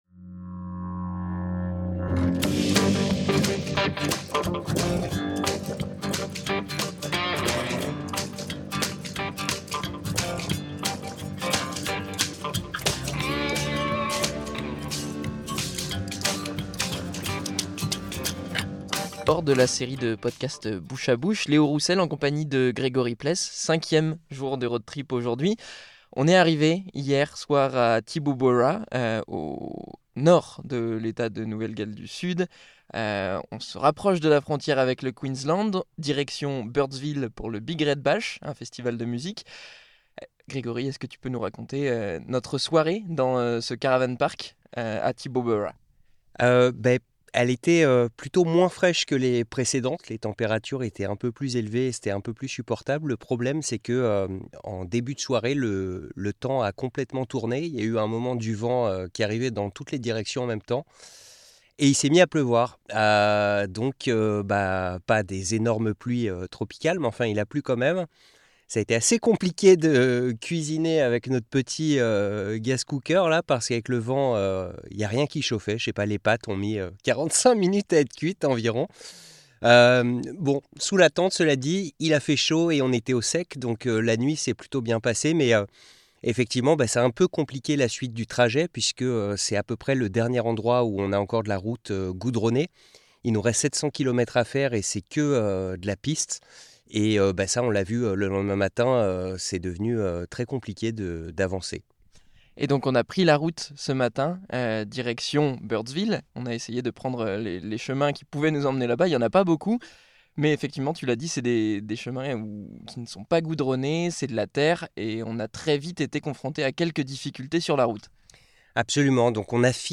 Entre pistes boueuses, routes fermées, et conditions météorologiques imprévisibles, la suite de notre trajet vers Birdsville et le festival du Big Red Bash est ralentie, voire incertaine. Un épisode enregistré au milieu de nulle part, mais à la frontière entre trois États, à Cameron Corner.